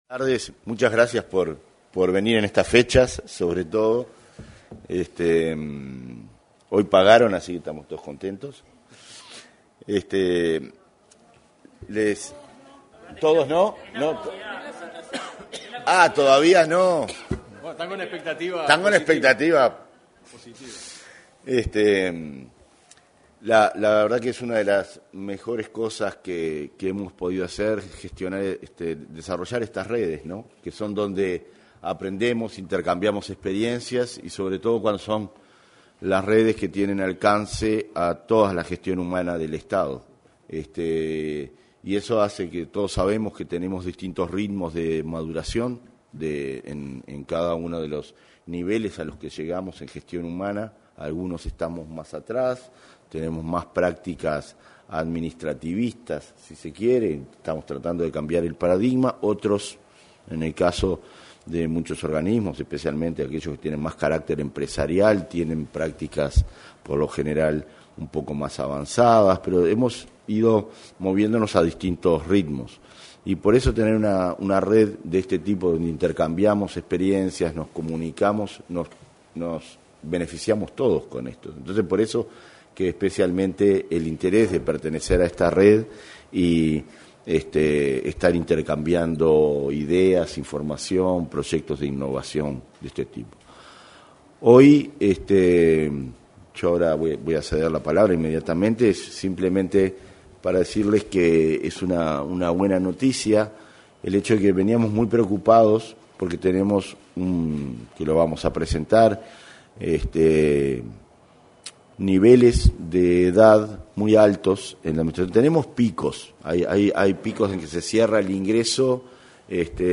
En el salón de actos de Torre Ejecutiva fue presentada una guía para la preparación de retiros por jubilación.